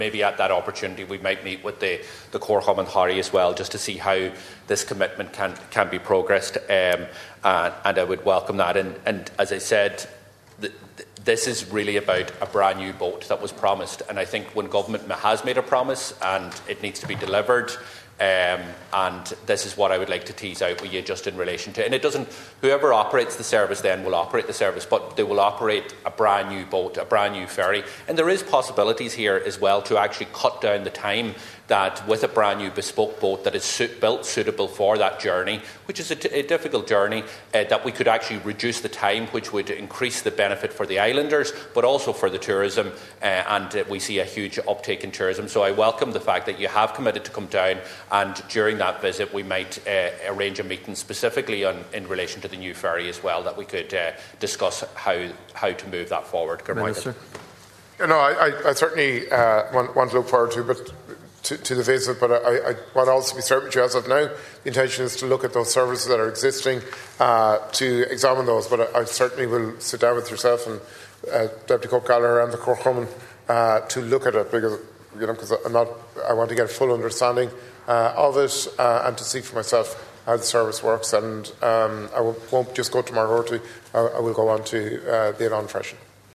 Minister Dara Calleary said he intends to visit Donegal to see how the service operates, which Deputy Doherty has welcomed: